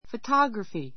photography A2 fətɑ́ɡrəfi ふォ タ グ ラふィ ｜ fətɔ́ɡrəfi ふォ ト グ ラふィ 名詞 写真撮影 さつえい （術） a photography shop a photography shop （現像などをする）写真屋さん My only hobby is photography.